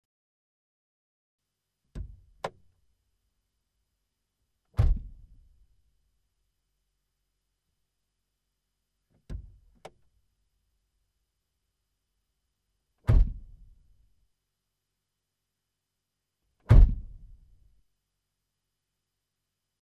Фольксваген гольф: Двери открывают и закрывают (изнутри)
Тут вы можете прослушать онлайн и скачать бесплатно аудио запись из категории «Двери, окна».